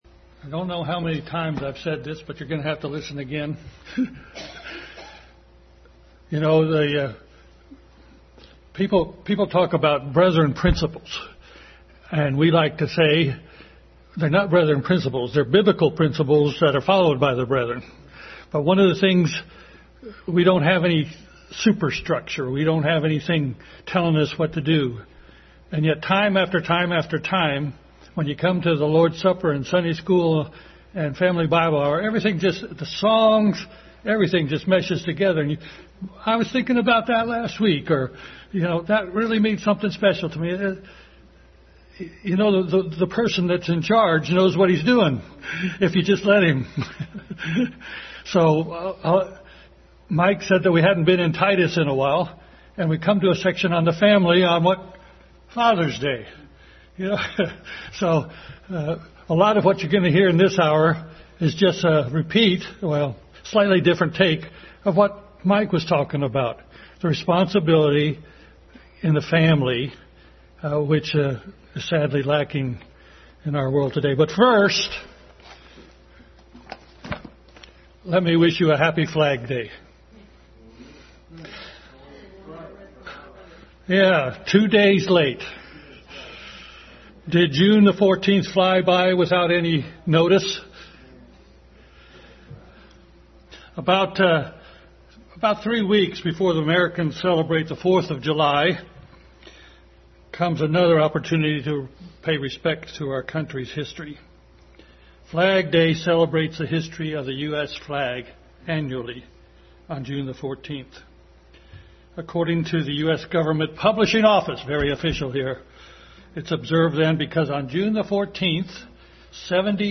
Flag Day & Father’s Day Passage: Various Scriptures Service Type: Family Bible Hour